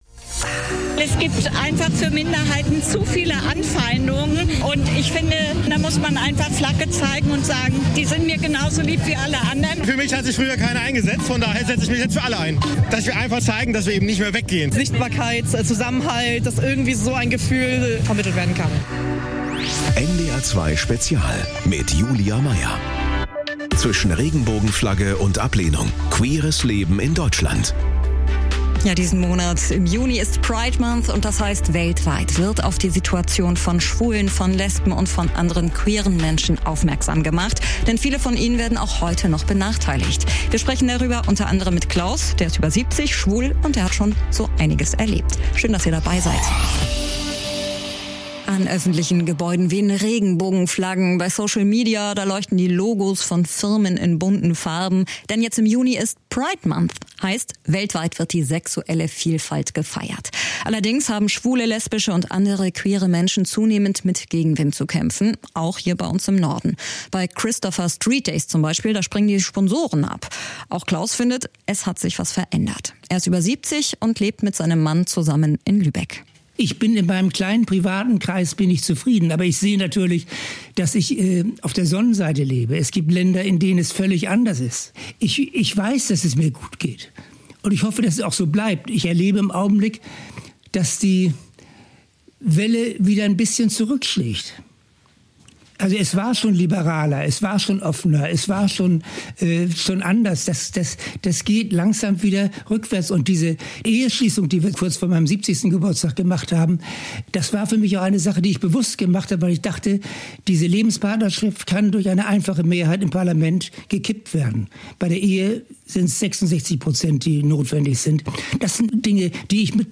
Regelmäßig am Donnerstag im Anschluss an die NDR 2 Nachrichten um 19 Uhr widmet sich NDR 2 ausführlich einem aktuellen Thema. Ob politischer Machtwechsel, Orkan oder Lebensmittelskandal - NDR 2 gibt einen umfassenden Überblick und spricht mit Experten, Korrespondenten und Betroffenen.